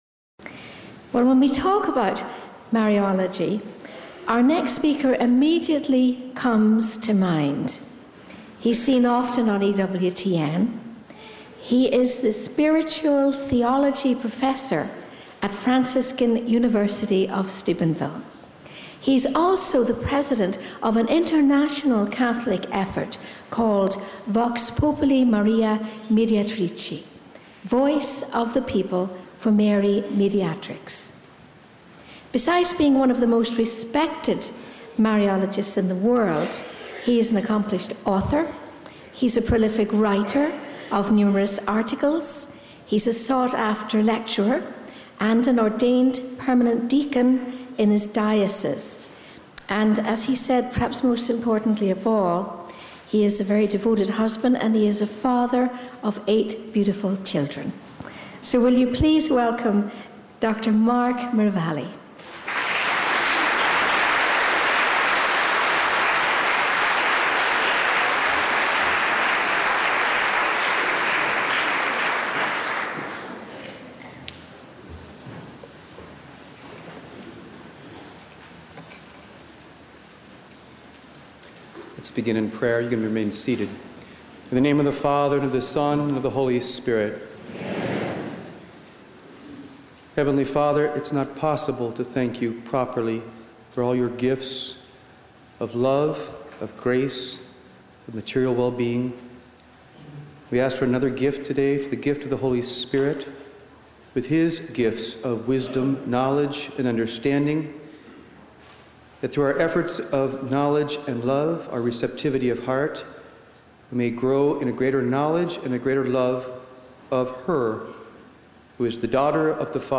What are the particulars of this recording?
At the Behold Your Mother Marian Conference at Our Lady of the Greenwood in Indiana in 2007